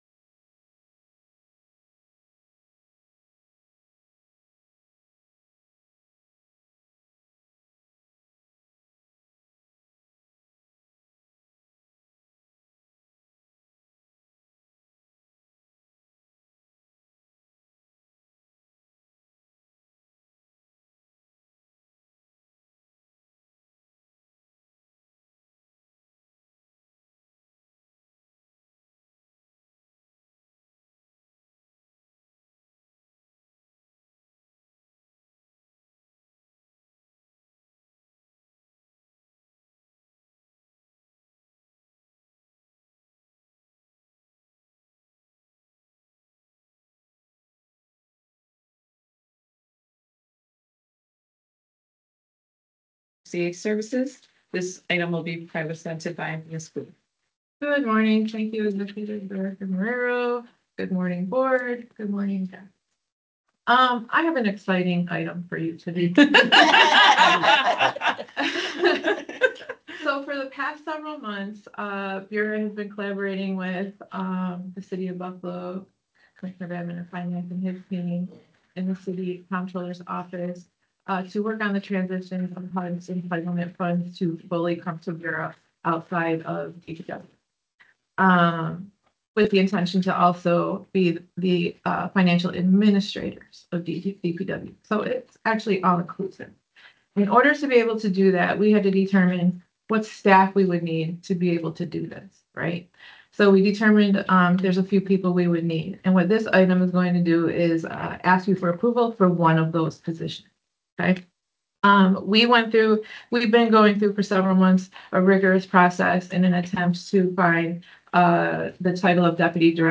All BURA board meetings take place at 9:30am in City Hall